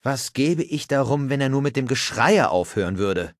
Charakter: Geisel des Predigers
Fallout 3: Audiodialoge